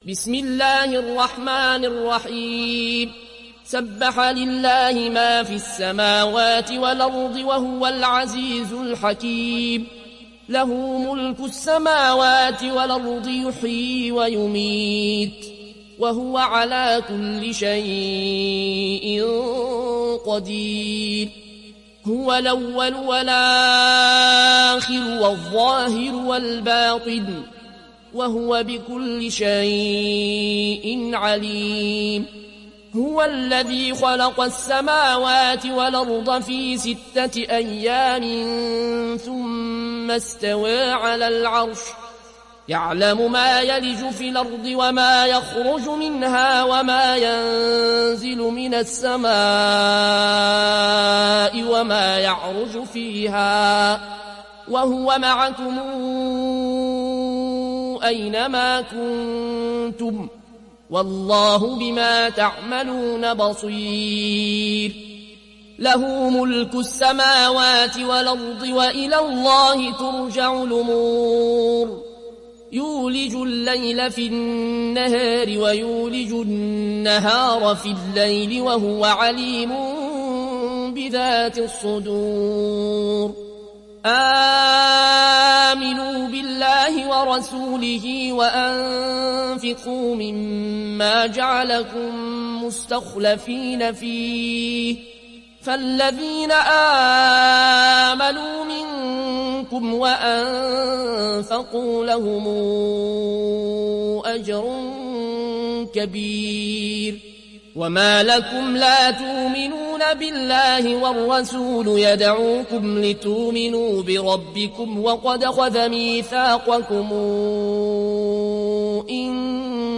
روایت ورش